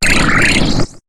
Cri de Clic dans Pokémon HOME .